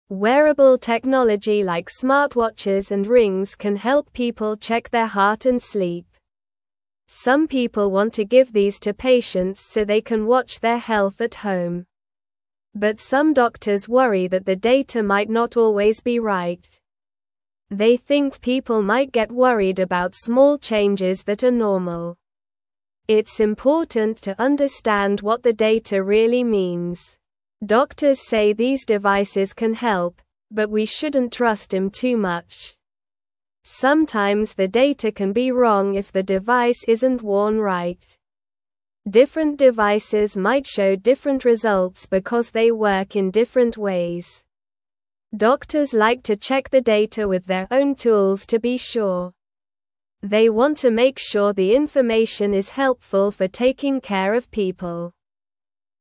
Slow
English-Newsroom-Beginner-SLOW-Reading-Smart-Devices-Help-Check-Health-at-Home.mp3